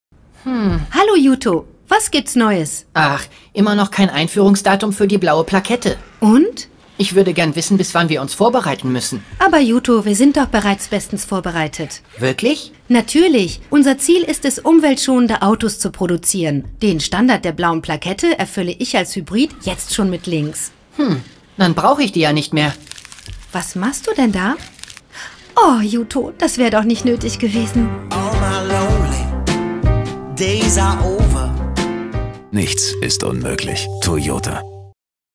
Sprecherin, Übersetzerin, Medienkauffrau
DE: Commercial [Toyota]